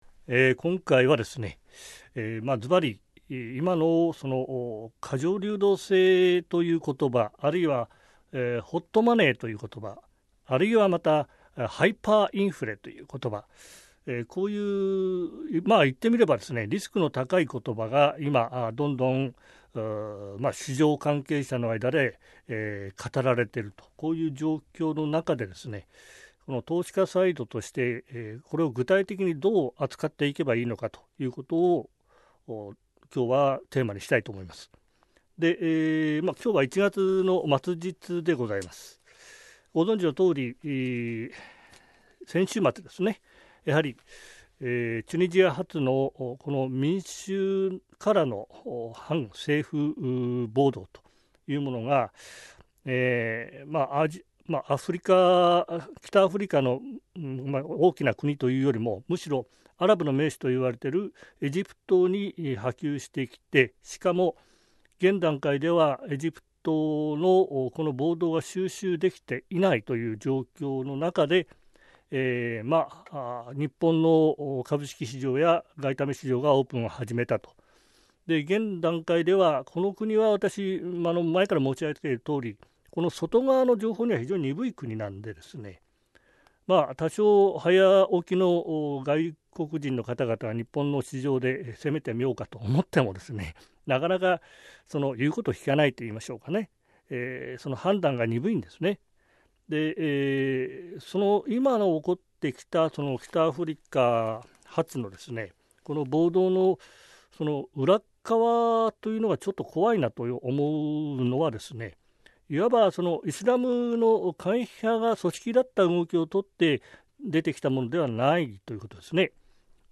[オーディオブックCD]